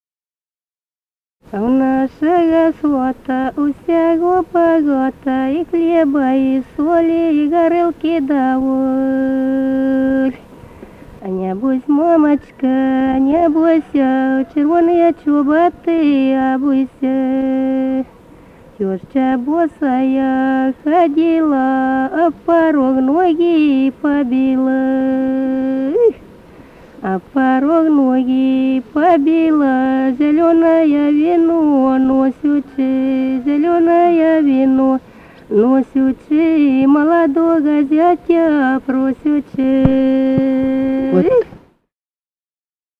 Музыкальный фольклор Климовского района 043. «А у нашего свата» (свадебная).
Записали участники экспедиции